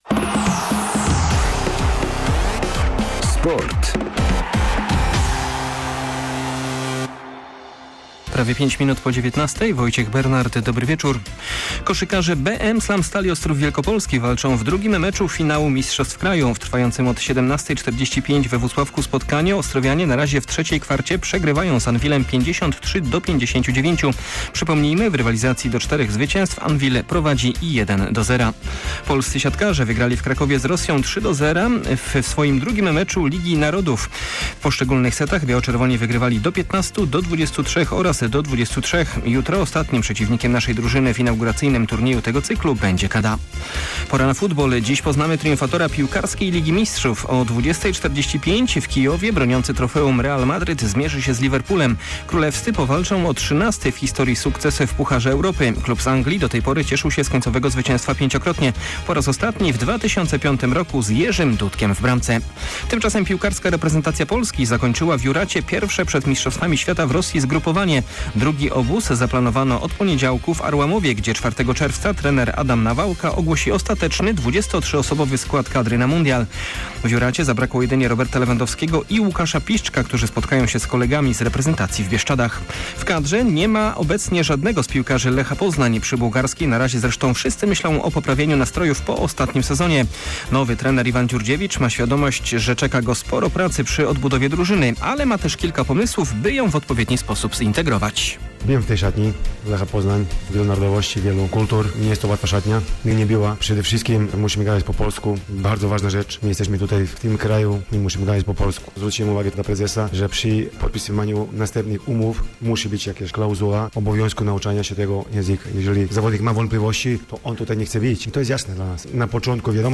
26.05 serwis sportowy godz. 19:05